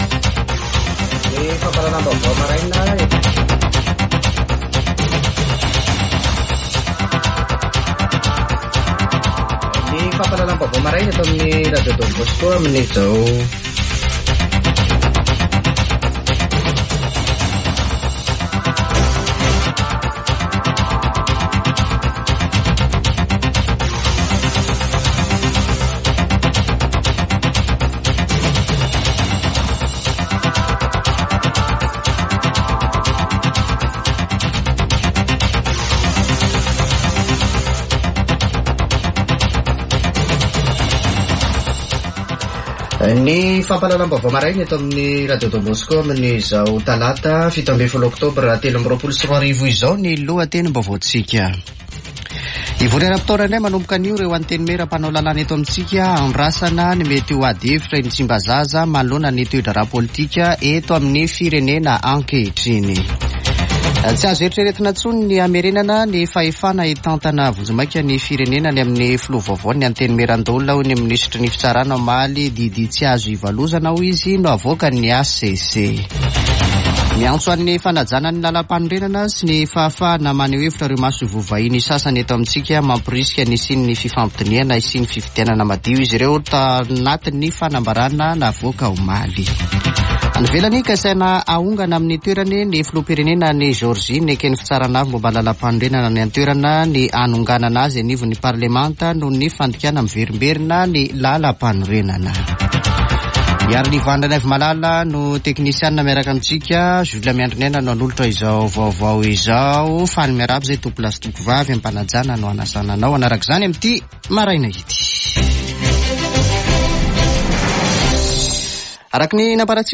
[Vaovao maraina] Talata 17 ôktôbra 2023